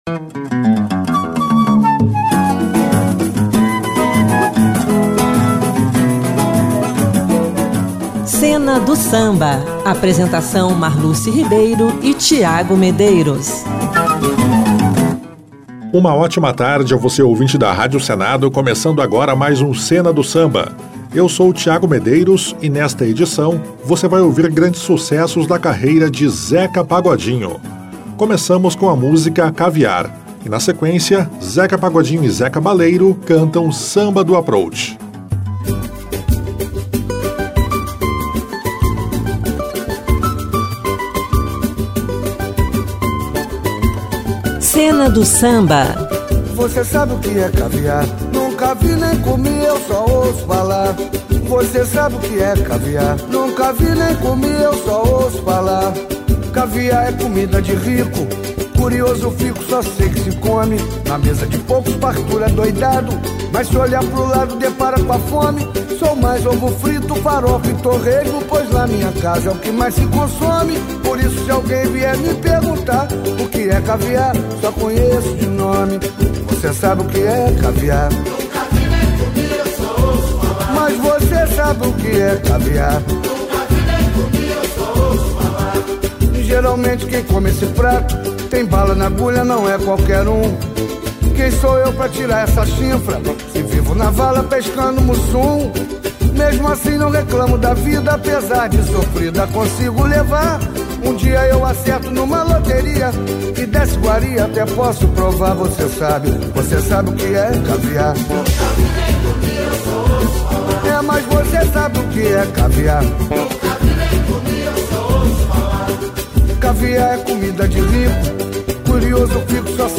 uma seleção musical